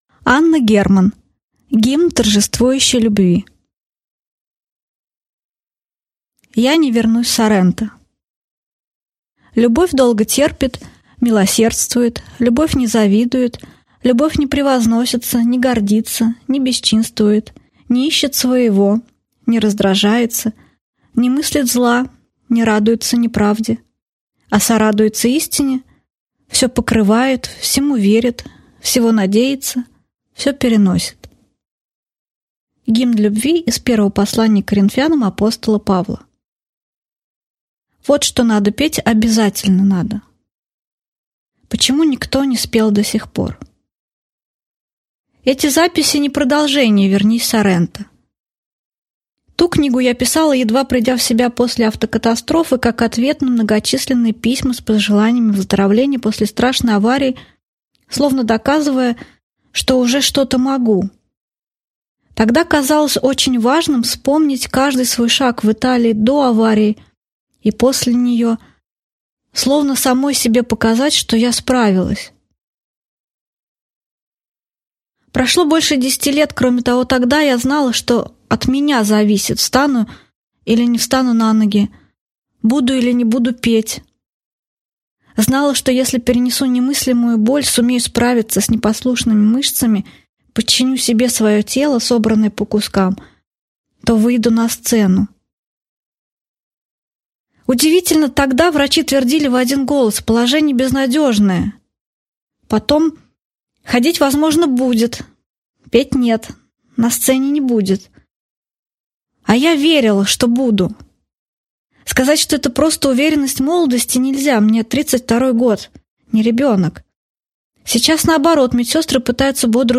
Аудиокнига Гимн торжествующей Любви | Библиотека аудиокниг